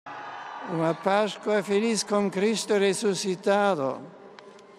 Os votos de Santa Páscoa 2010, expressos por Bento XVI na nossa língua, no final da solene celebração eucarística, na Praça de São Pedro, e após a Mensagem dirigida a todo o mundo da varanda central da basílica.